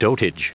Prononciation du mot dotage en anglais (fichier audio)
Prononciation du mot : dotage